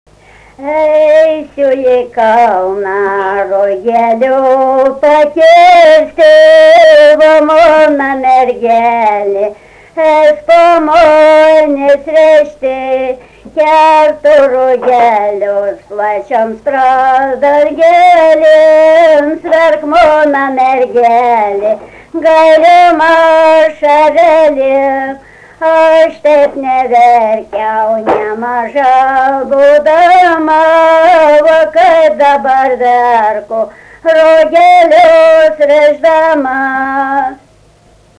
daina, kalendorinių apeigų ir darbo